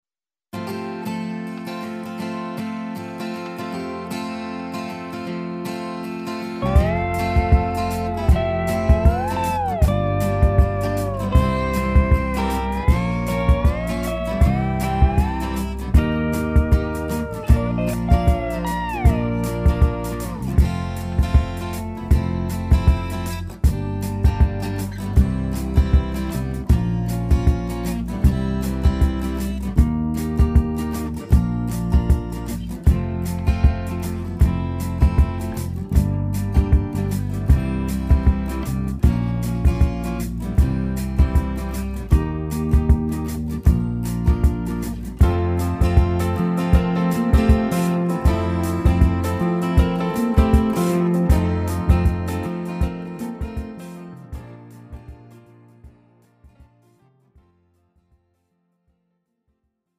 (팝송) MR 반주입니다.